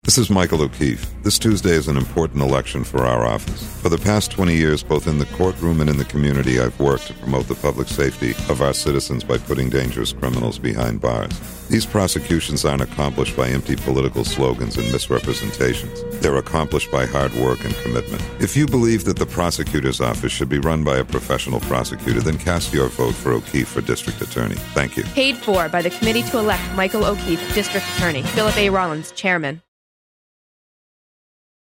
Tracks 3 and 4 are audio sent in to accompany a couple of responses to this month's Q It Up question – read then listen!